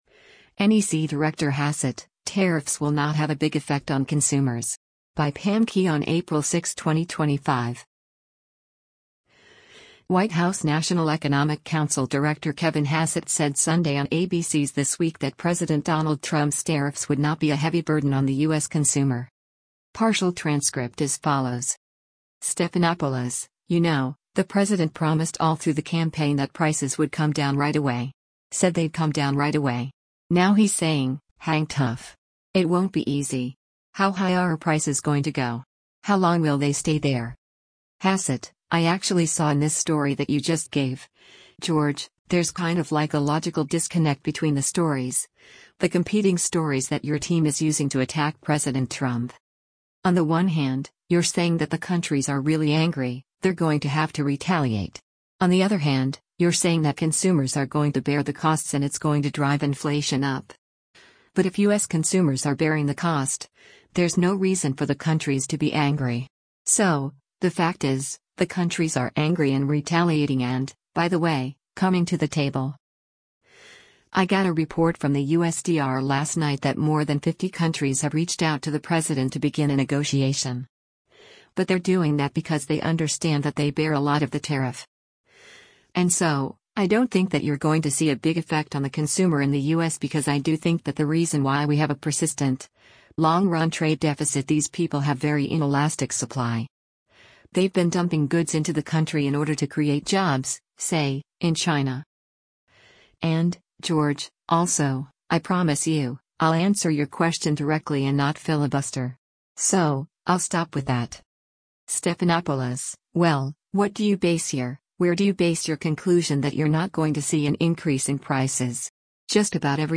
White House National Economic Council director Kevin Hassett said Sunday on ABC’s “This Week” that President Donald Trump’s tariffs would not be a “heavy burden on the U.S. consumer.”